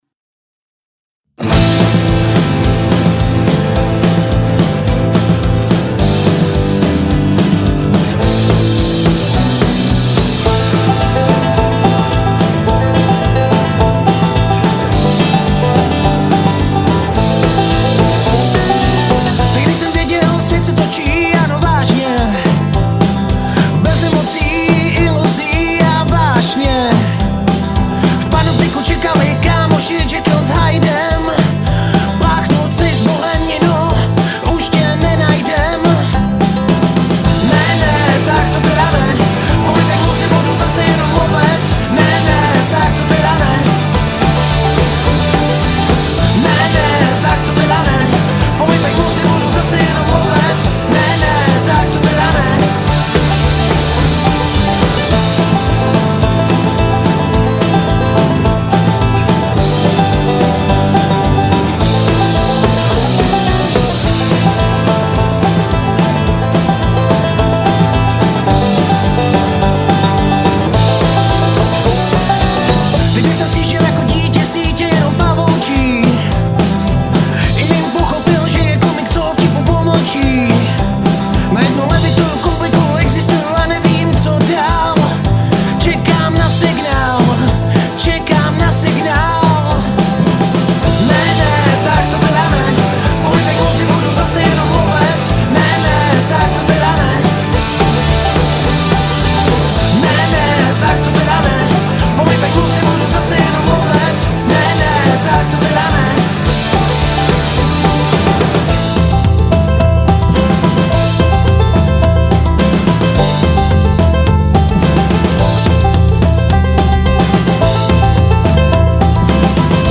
Žánr: Rock
pop-rockové